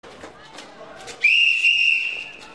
Whistle Free sound effects and audio clips
Refferee_blows_whistle_EgN.wav